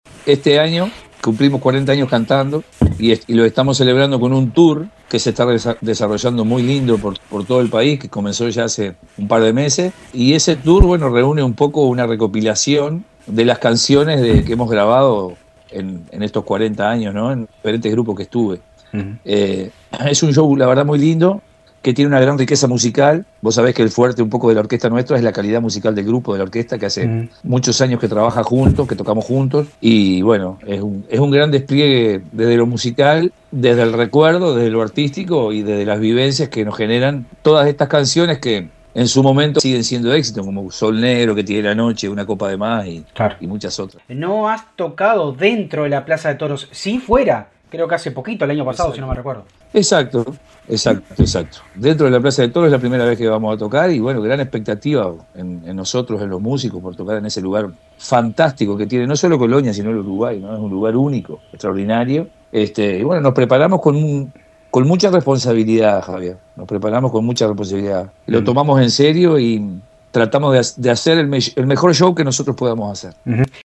Hablamos con el artista en la previa de show de esta noche, en donde se mostró con muchas expectativas por el espectáculo que brindará en el recinto histórico…